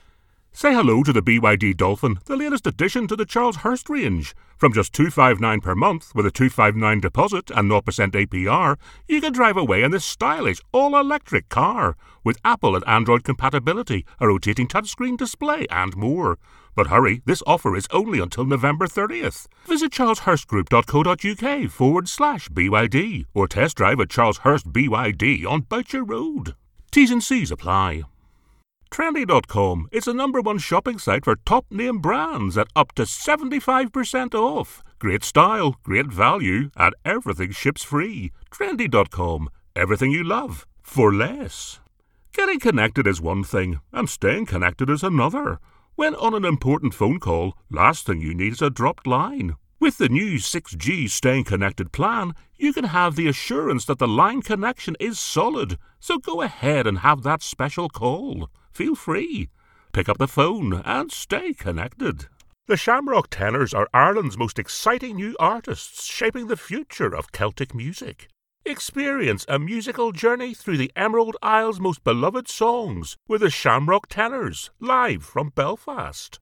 Never any Artificial Voices used, unlike other sites.
Radio & TV Commercial Voice Overs Talent, Artists & Actors
English (Irish)
Adult (30-50) | Older Sound (50+)